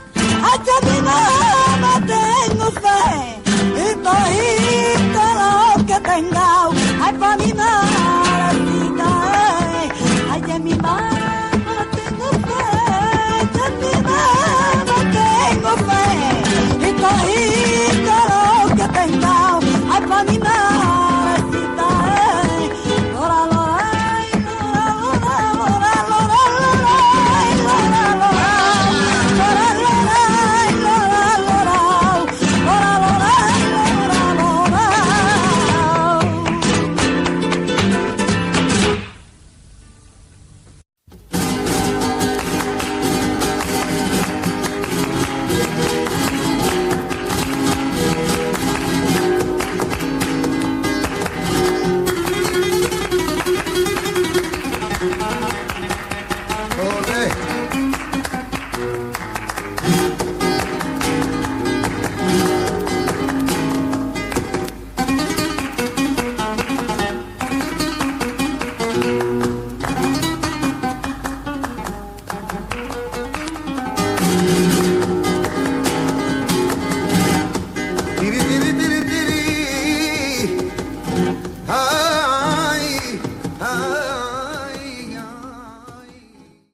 Música flamenca